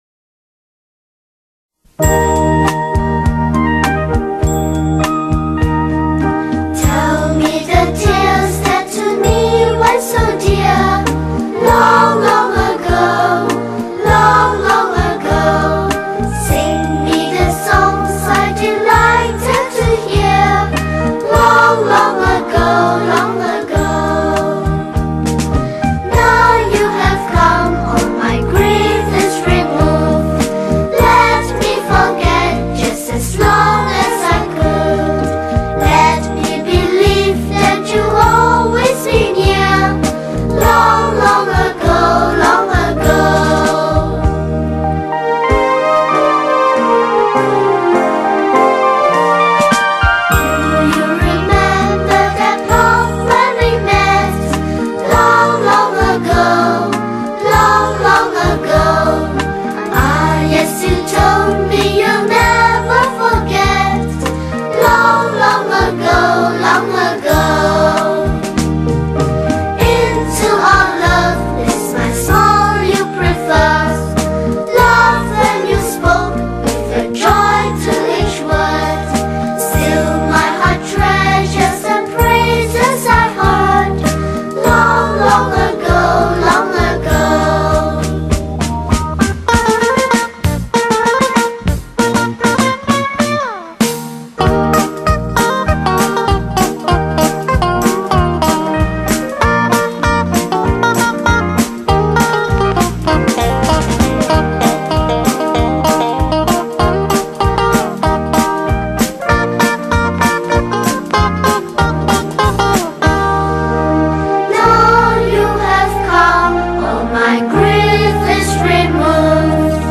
Children Song , English Old Folk Song
Skor Angklung